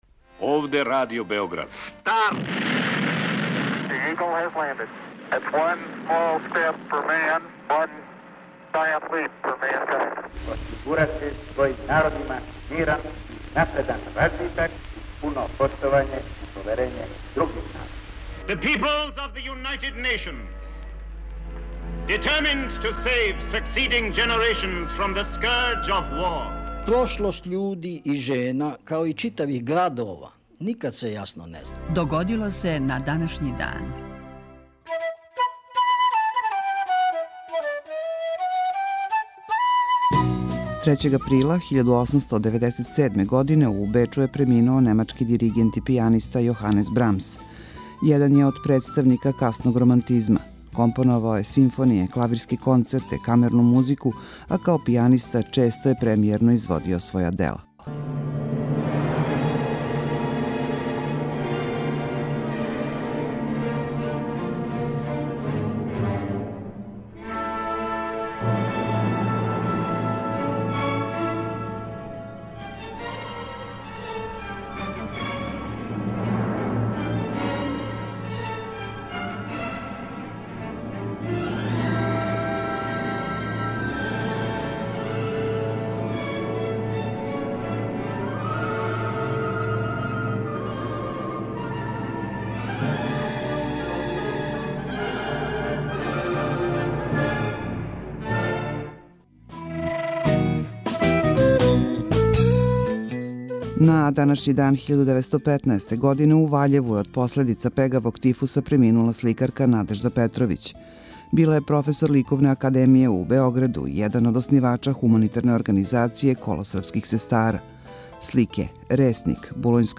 Емисија Догодило се на данашњи дан, једна од најстаријих емисија Радио Београда свакодневни је подсетник на људе и догађаје из наше и светске историје. У 5-томинутном прегледу, враћамо се у прошлост и слушамо гласове људи из других епоха.